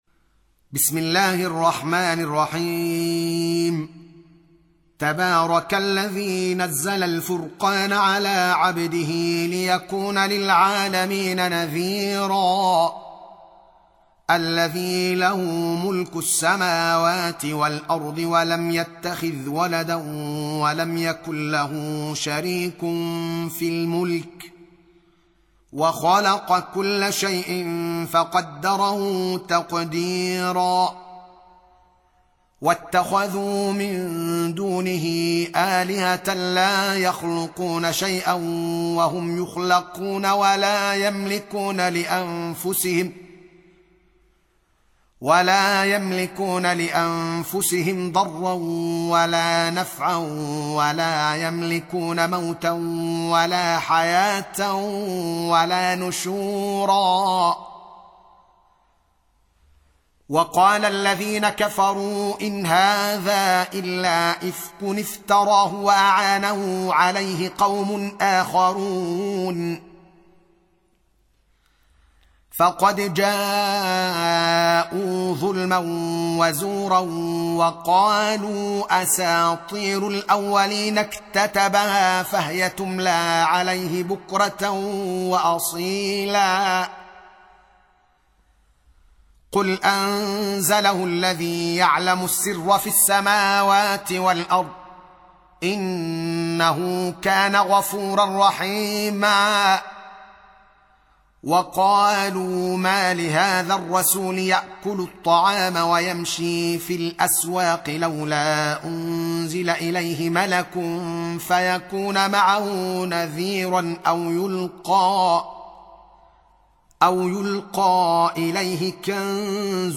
Surah Repeating تكرار السورة Download Surah حمّل السورة Reciting Murattalah Audio for 25. Surah Al-Furq�n سورة الفرقان N.B *Surah Includes Al-Basmalah Reciters Sequents تتابع التلاوات Reciters Repeats تكرار التلاوات